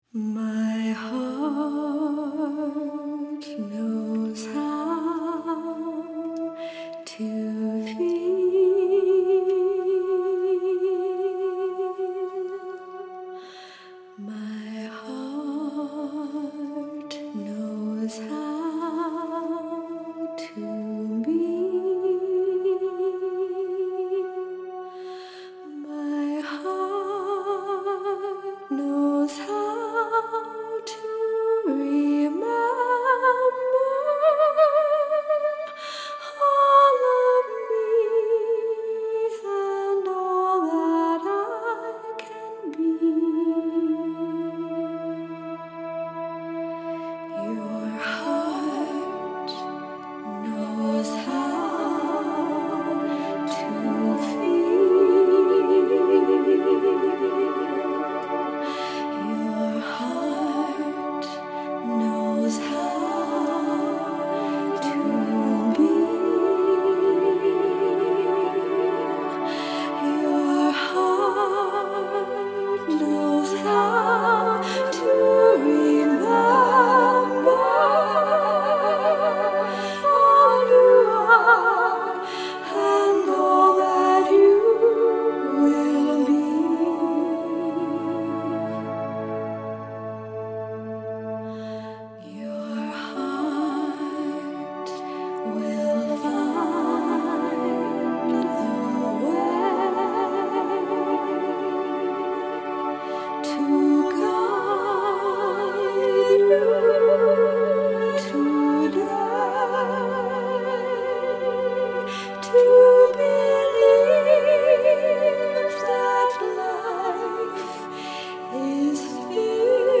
Soundscape